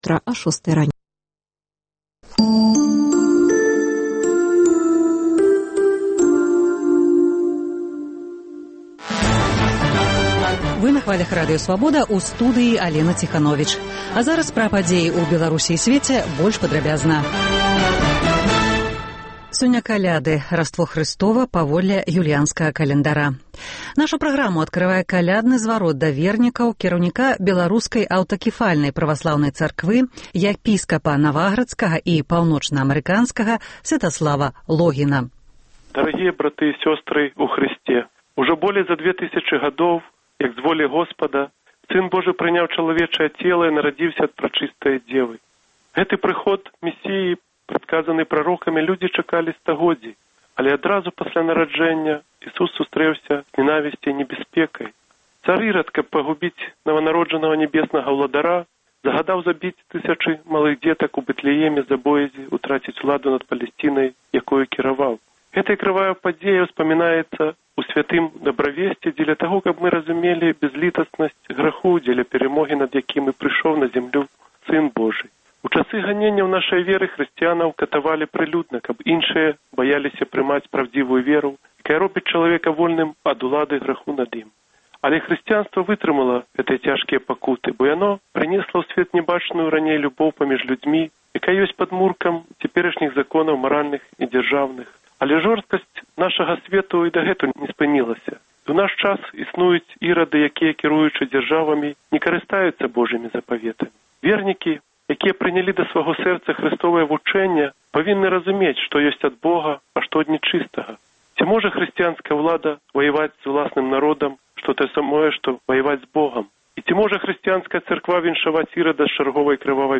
Інфармацыйны блёк: навіны Беларусі і сьвету Паведамленьні нашых карэспандэнтаў, званкі слухачоў, апытаньні ў гарадах і мястэчках Беларусі